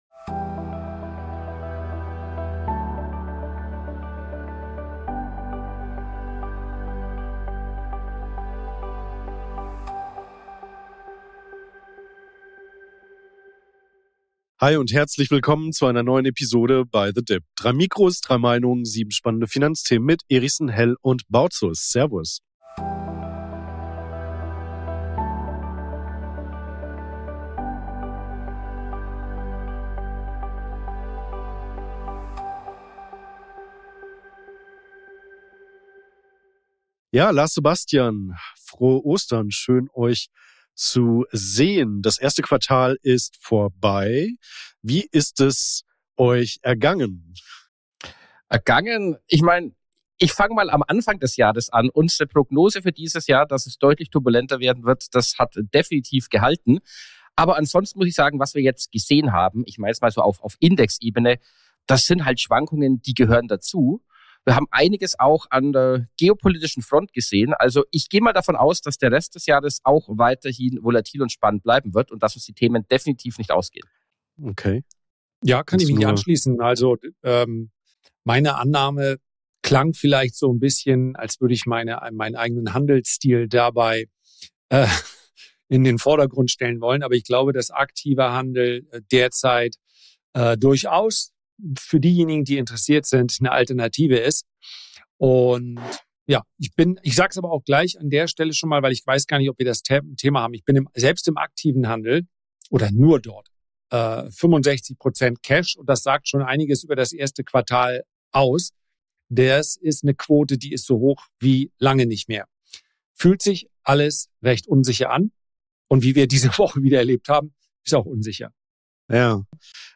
Auch diese Woche begrüßen wir euch unter dem Motto „3 Mikrofone, 3 Meinungen“ zu den folgenden Themen in dieser Ausgabe: Ist das jetzt die große Bullenfalle?